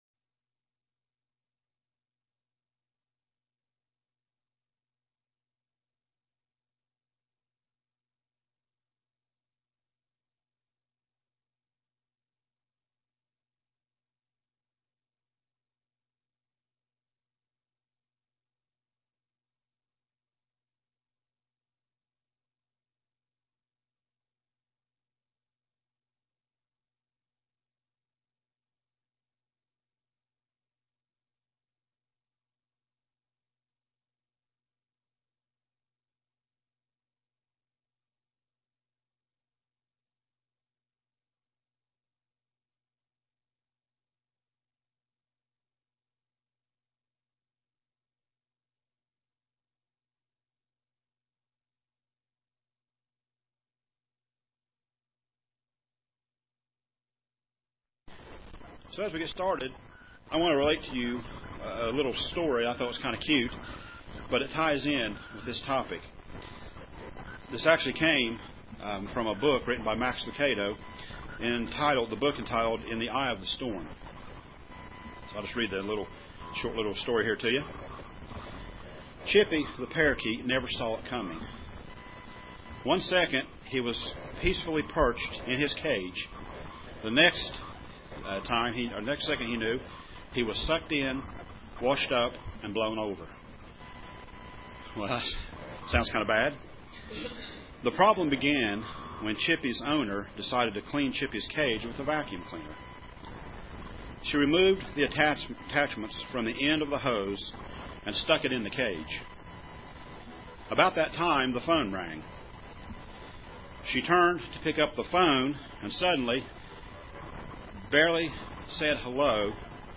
Given in Paintsville, KY
UCG Sermon Studying the bible?